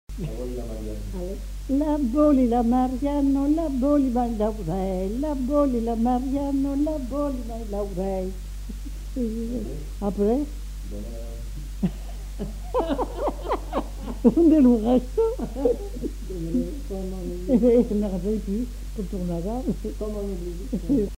Aire culturelle : Haut-Agenais
Lieu : Cancon
Genre : chant
Effectif : 1
Type de voix : voix de femme
Production du son : chanté
Danse : bourrée